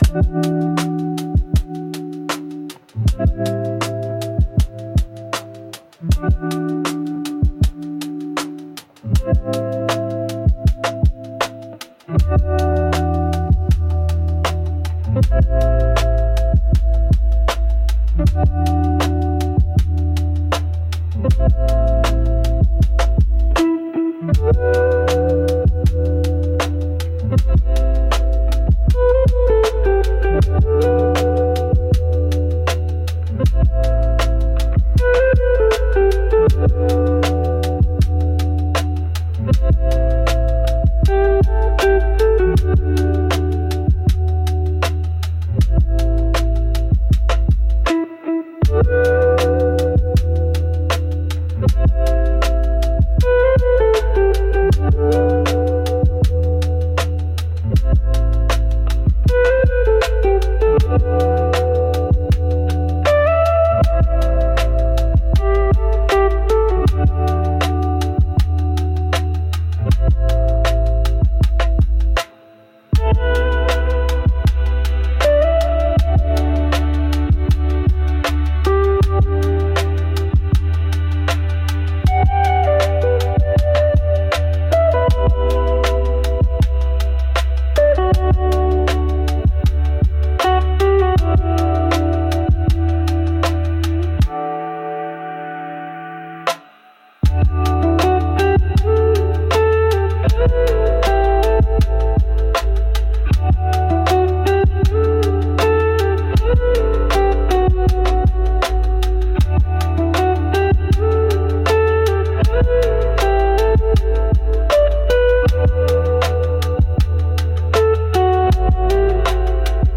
E Minor – 79 BPM
Chill
Hip-Hop
Lofi